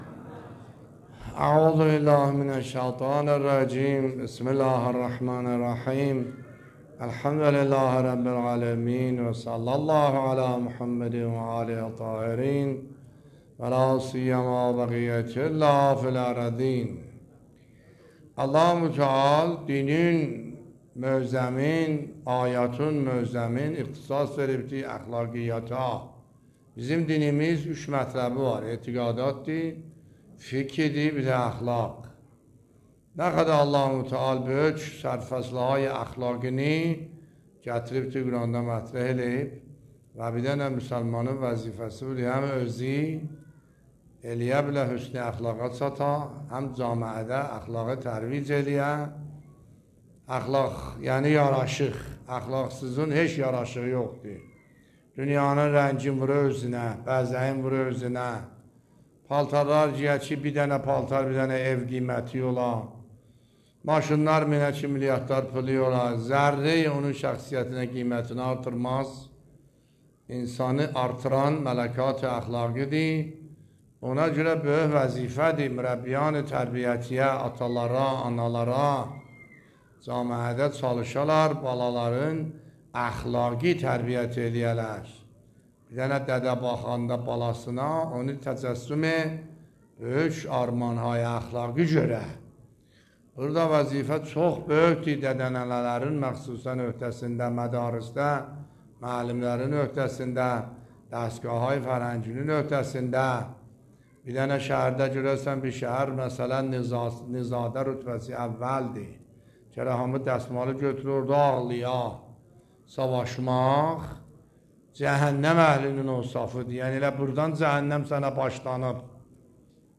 نکات برگزیده تفسیری آیه 53 سوره مبارکه اسراء در بیان دلنشین آیت الله سید حسن عاملی در مسجد مرحوم میرزا علی اکبر در بیست و نهمین شب رمضان المبارک 1402 به مدت 25 دقیقه